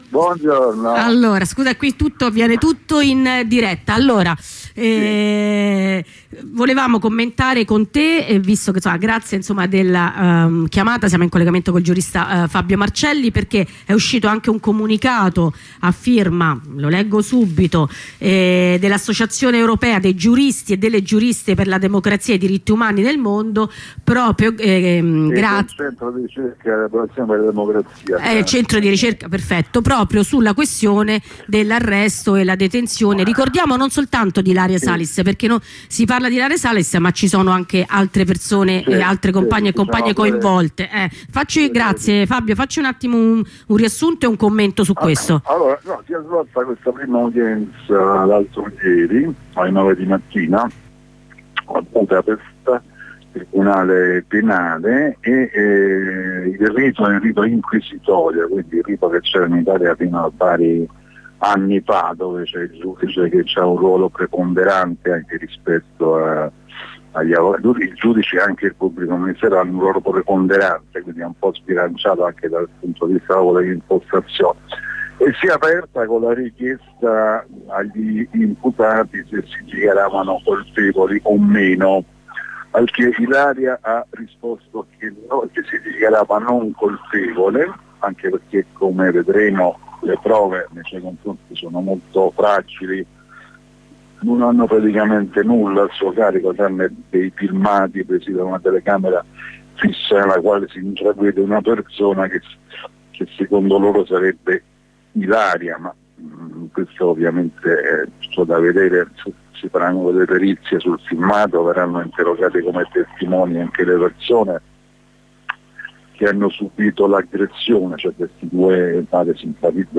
Intervento dal presidio